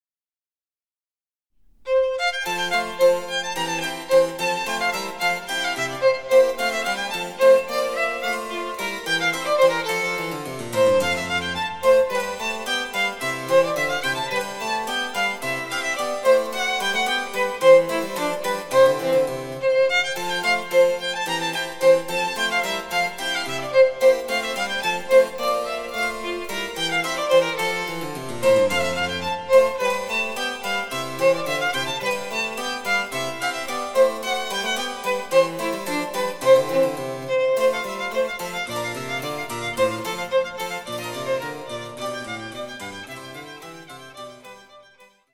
■ヴァイオリンによる演奏
チェンバロ（電子楽器）